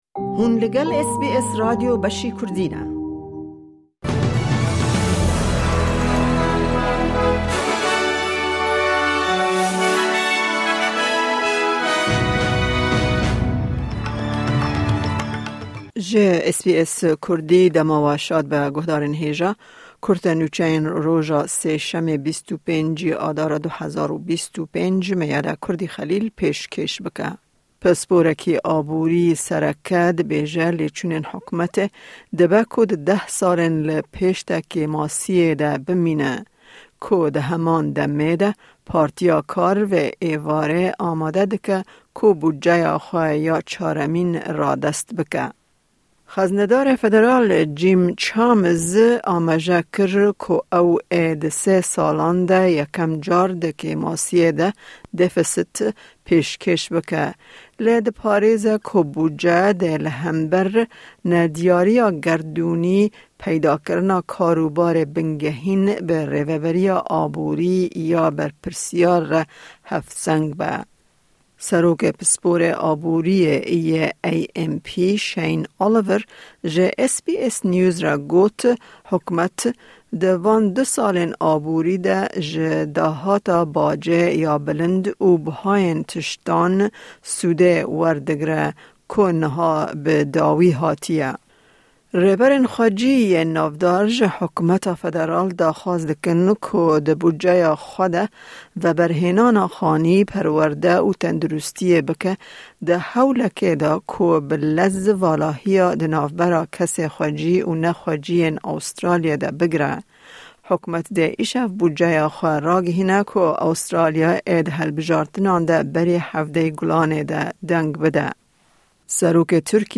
Kurte Nûçeyên roja Sêşemê 25 Adara 2025